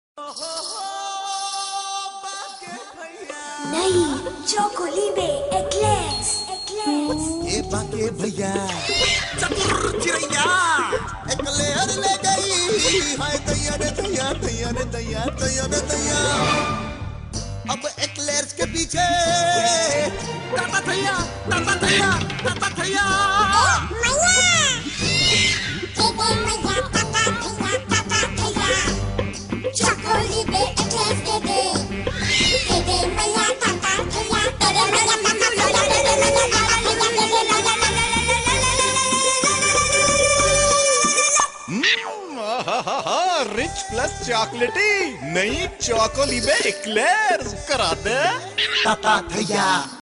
File Type : Tv confectionery ads